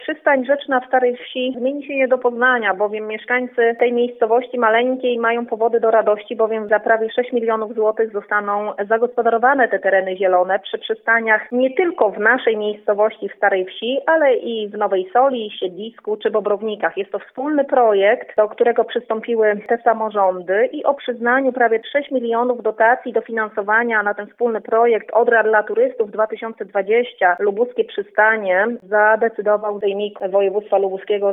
– Przystań zostanie zagospodarowana między innymi w Starej Wsi, gdzie są piękne tereny zielone – powiedziała Izabela Bojko, wójt gminy wiejskiej Nowa Sól: